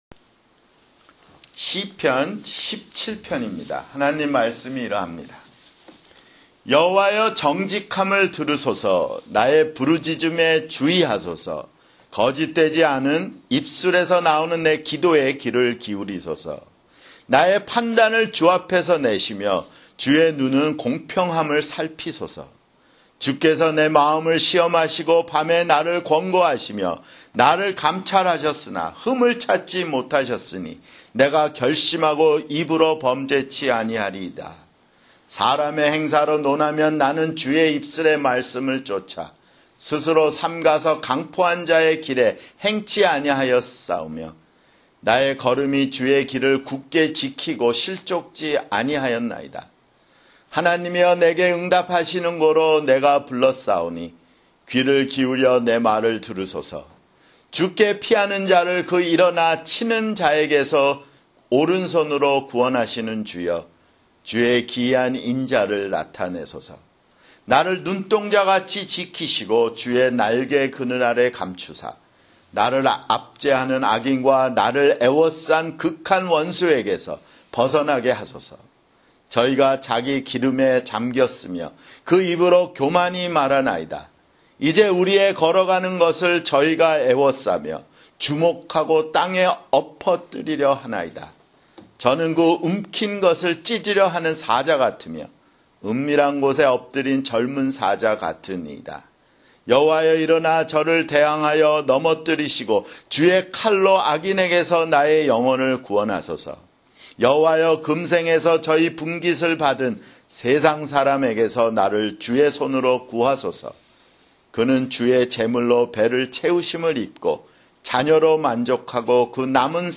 [주일설교] 시편 (15)